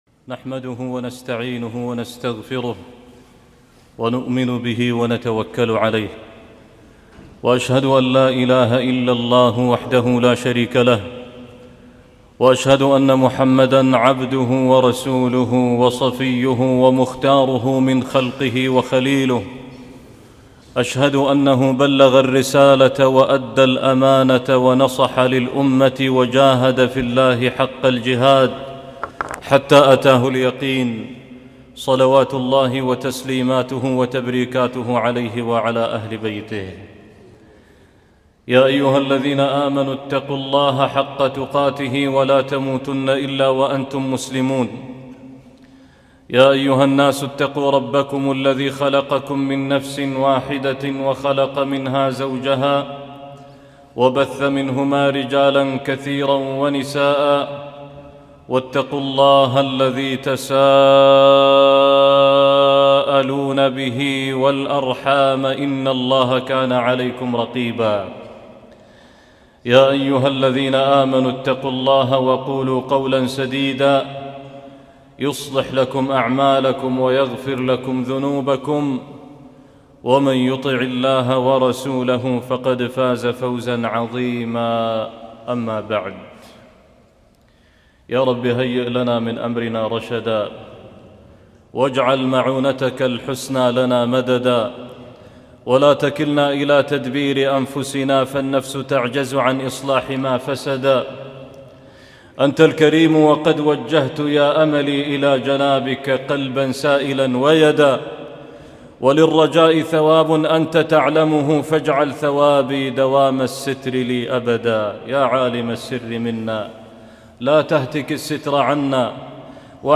صلاة الروح - خطبة الجمعة